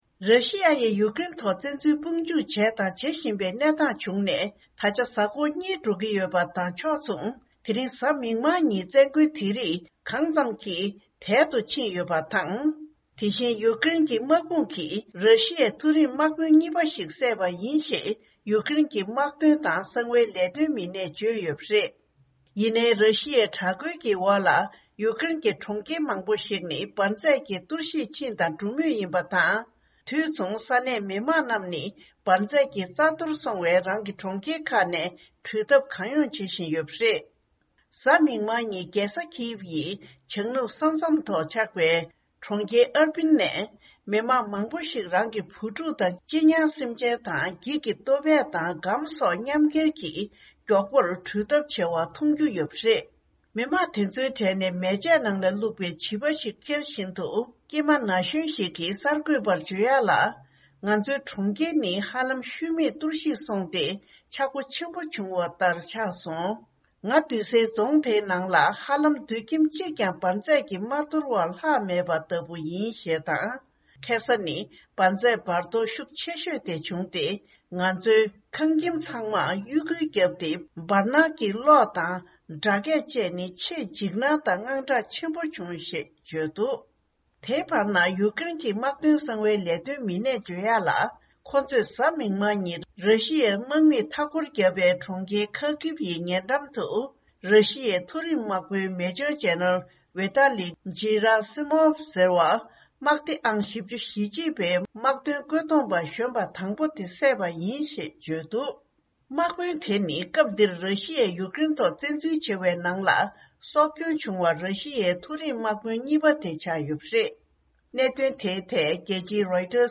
ཕྱོགས་བསྒྲིགས་དང་སྙན་སྒྲོན་ཞུ་ཡི་རེད།